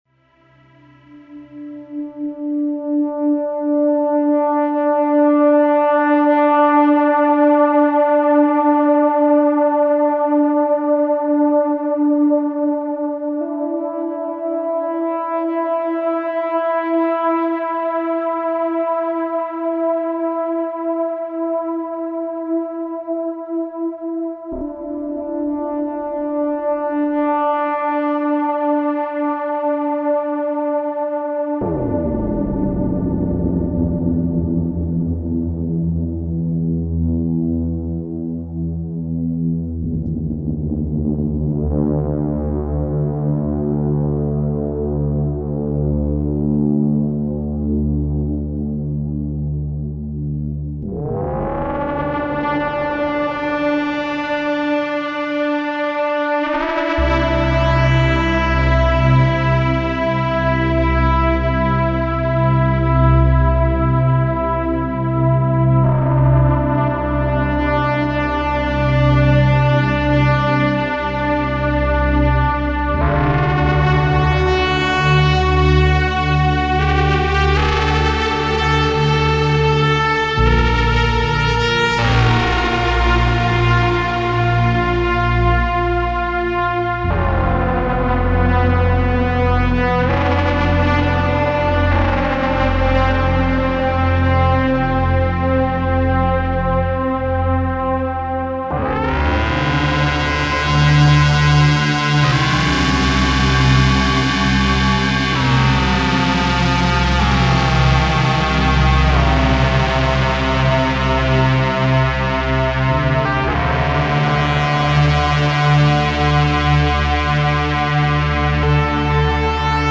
Hier nur eine kurze Improvisation von Heute Nacht. Ich habe mit dem Ringmodulator vom SonicSix experimentiert. Also Melodie ist der MOOG und das Pad ist VSTI Synth1...